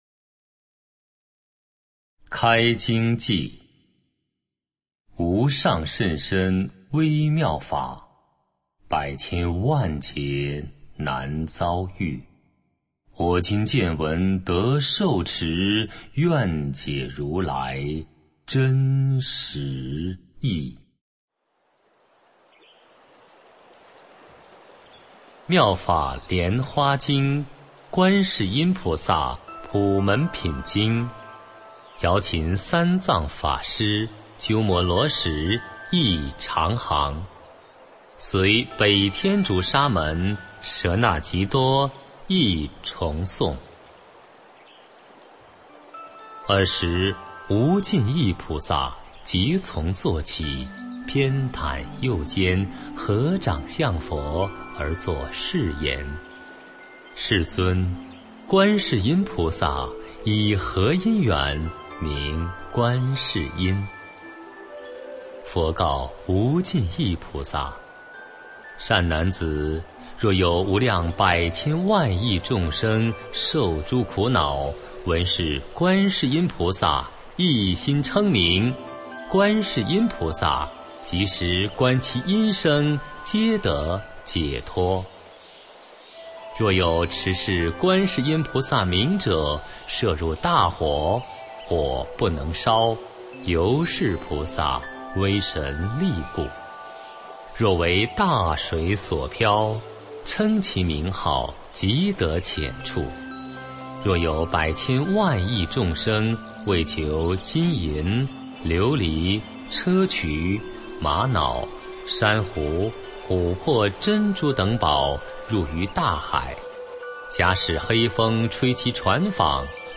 普门品读诵（本地音频） - 佛乐诵读
站内可直接播放的《普门品》读诵，适合观音法门专题听诵与配合经文阅读。
pumenpin-songdu.mp3